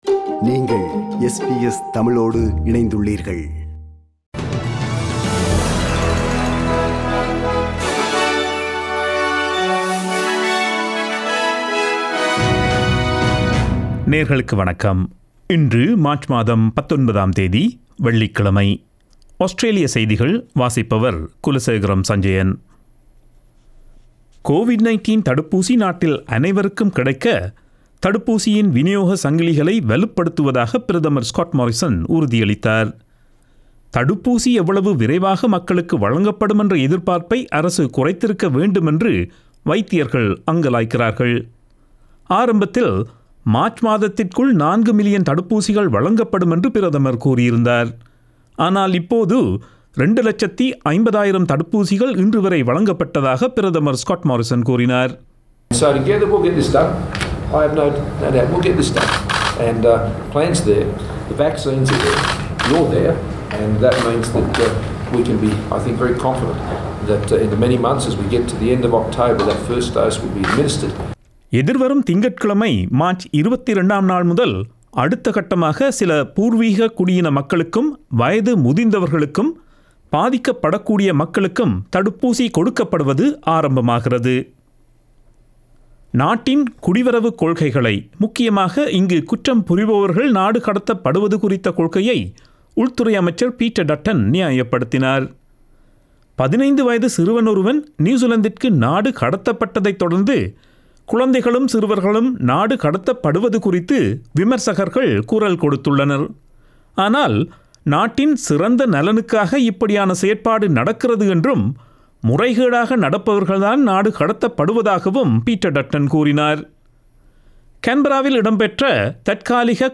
Australian news bulletin for Friday 19 March 2021.